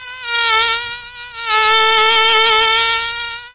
File Name: Mosquito.mp3